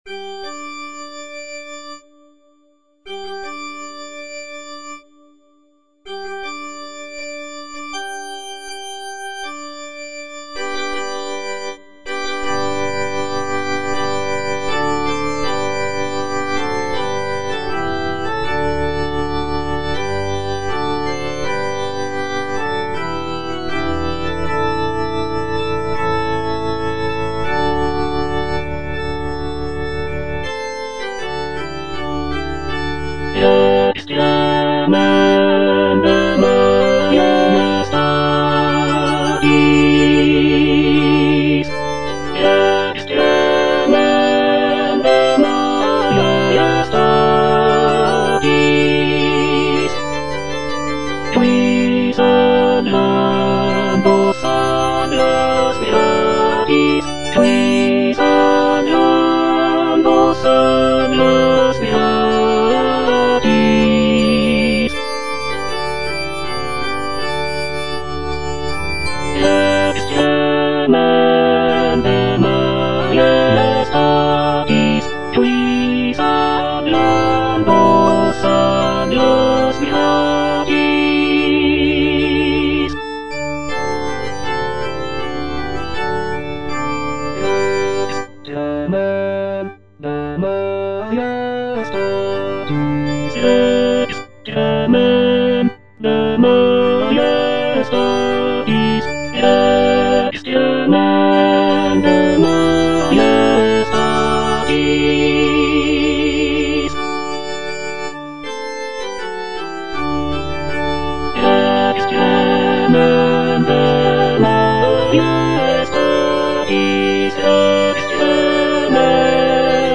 tenor II) (Emphasised voice and other voices) Ads stop
is a sacred choral work rooted in his Christian faith.